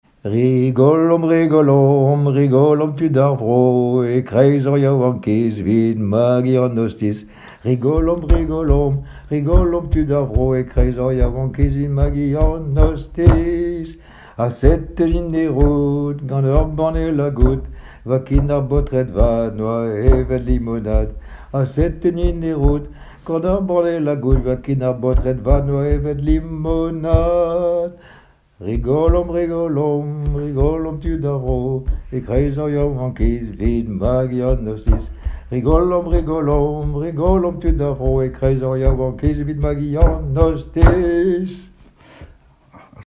Genre brève
Témoignages et chansons
Pièce musicale inédite